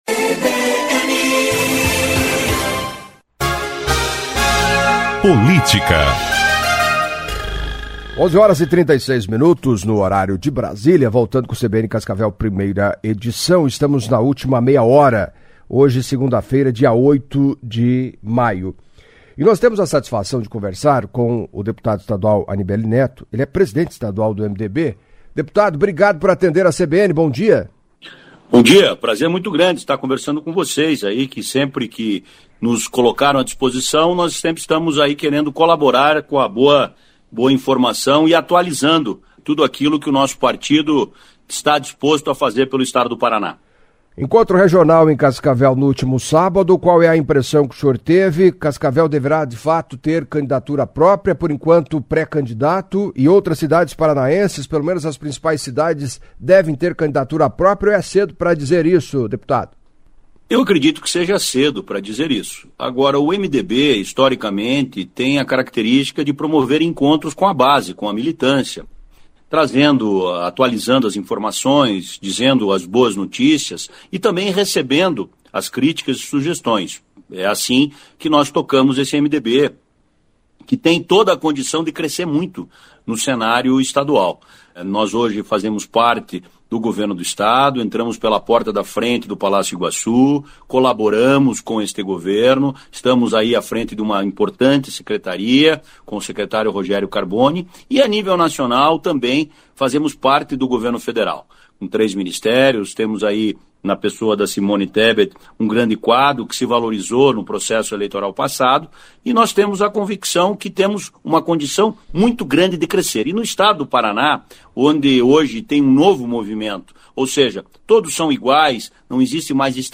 Em entrevista à CBN Cascavel nesta segunda-feira (08) o deputado estadual, Anibelli Neto, presidente do MDB no Paraná, falou do encontro regional realizado em Cascavel no sábado (06), na Câmara Municipal. Entre outros temas, o encontro serviu para o MDB lançar o deputado estadual, Oziel Luiz Batatinha, como pré-candidato à prefeitura de Cascavel.